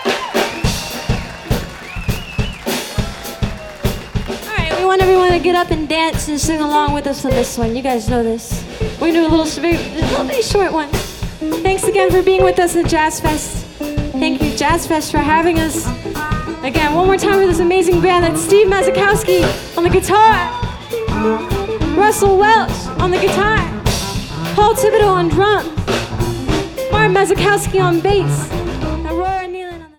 New Orleans, LA - April 25, 2014 Lagniappe Stage